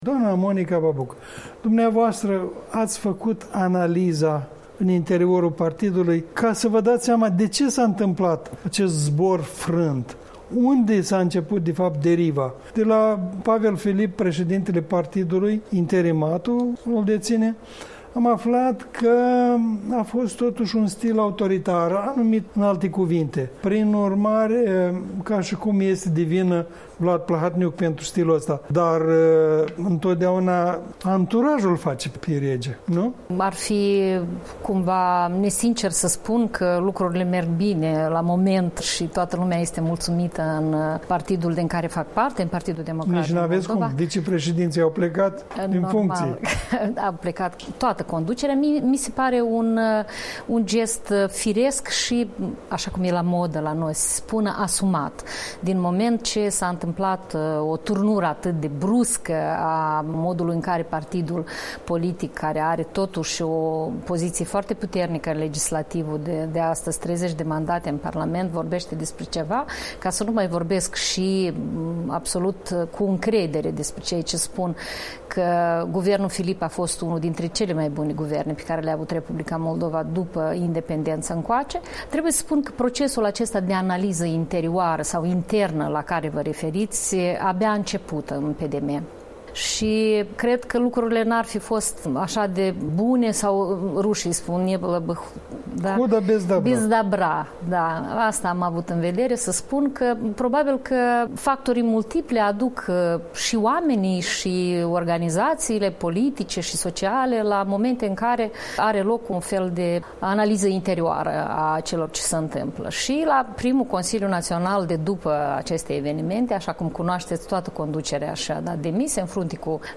Un interviu cu deputata PD, Monica Babuc, vicepreședintă a Parlamentului.